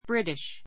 British brítiʃ ブ リ ティ シュ 形容詞 英国の, イギリス（人）の 類似語 English は厳密には「イングランド（人）の」という意味.